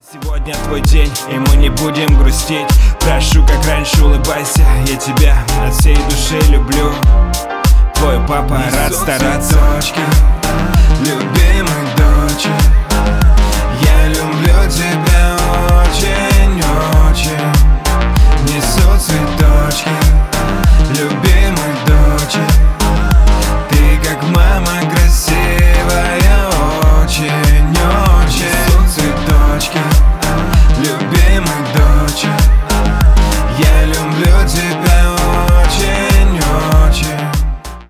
бесплатный рингтон в виде самого яркого фрагмента из песни
Поп Музыка